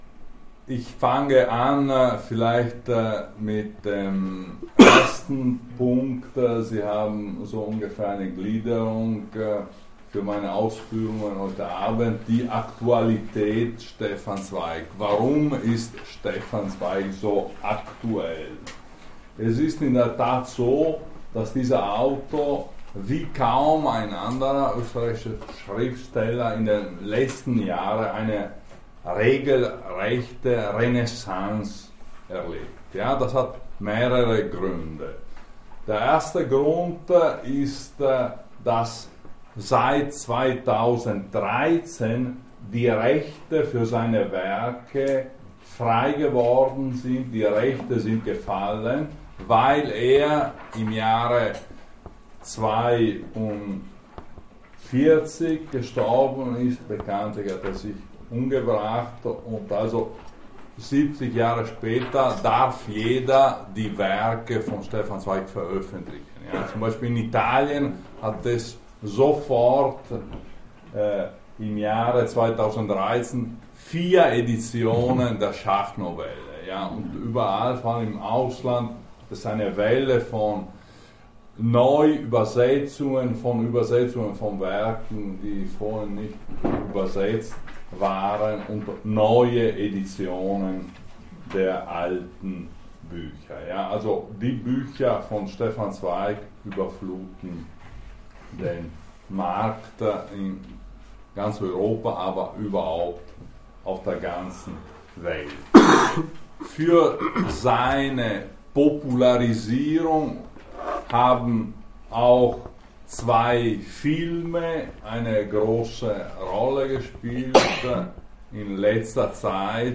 Jänner 2020 in der AKADEMIE am DOM einen Vortrag über den Europäer Stefan Zweig.